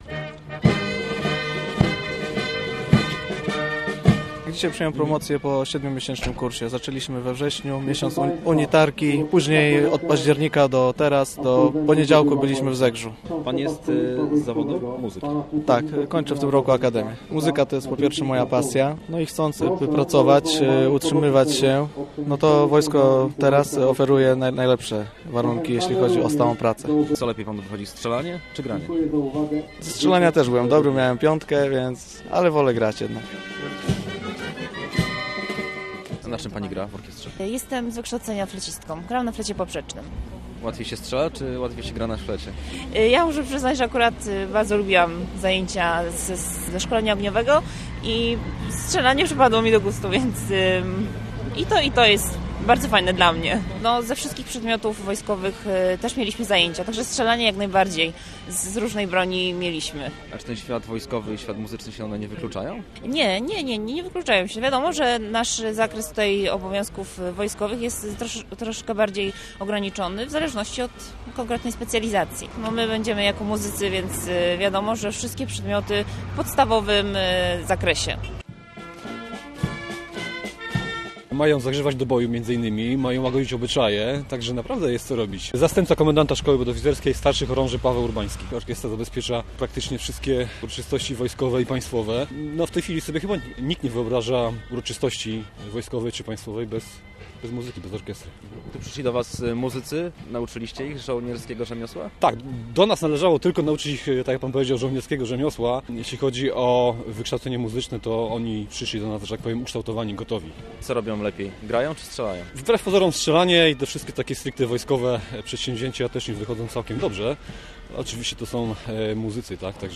Kilkunastu kadetów - muzyków orkiestr wojskowych odebrało dziś w Poznaniu promocje na pierwszy stopień podoficerski. Uroczystość - w zimowej scenerii - odbyła się przy Pomniku Armii Poznań.